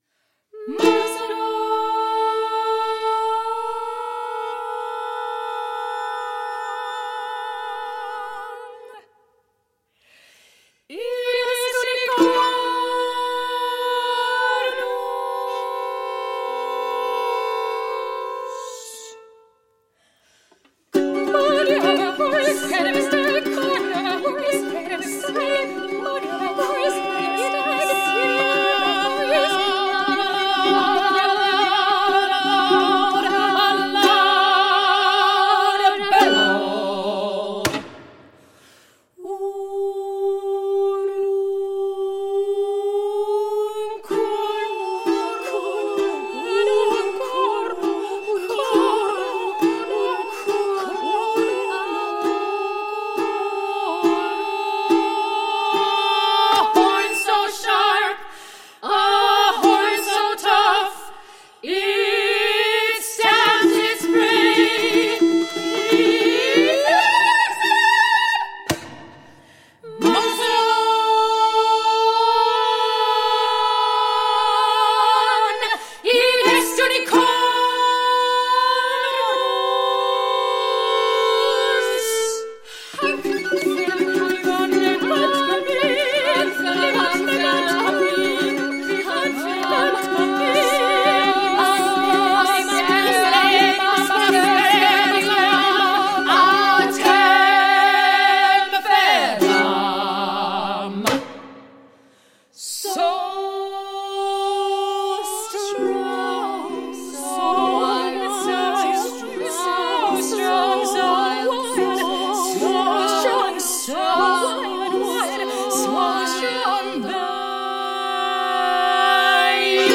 • Genres: Classical, Opera
soprano & violin